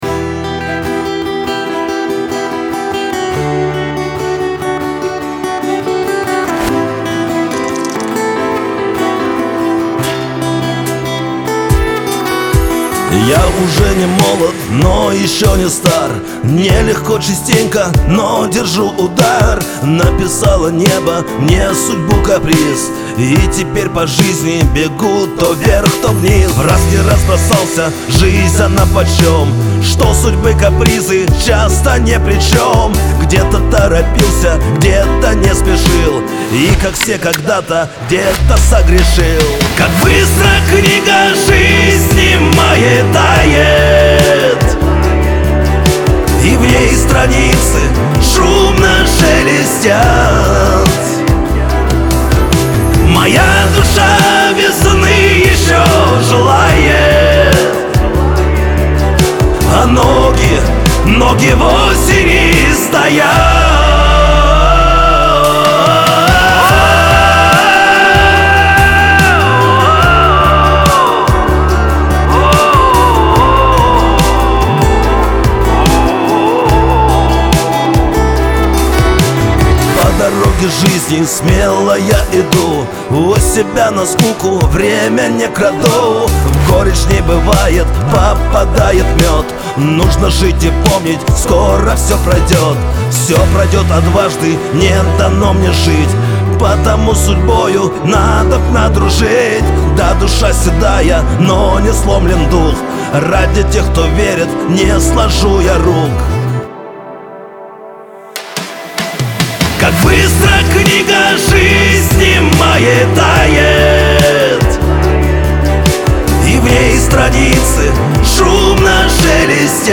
грусть , эстрада
pop